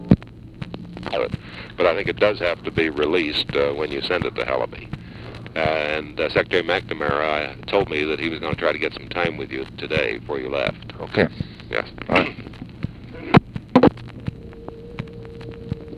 Conversation with UNIDENTIFIED MALE, April 23, 1964
Secret White House Tapes